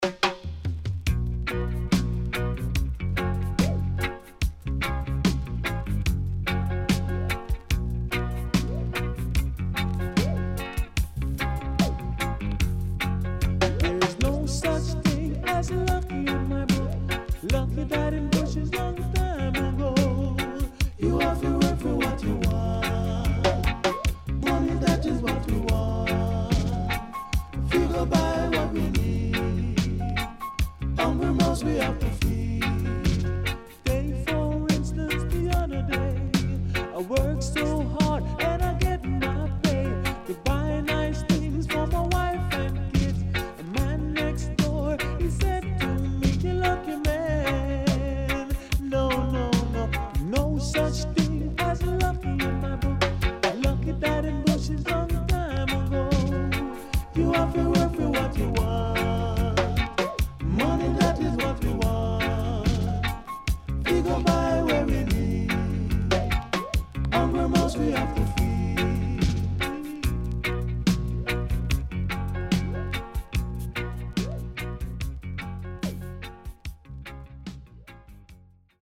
CONDITION SIDE A:VG+
SIDE A:少しチリノイズ入りますが良好です。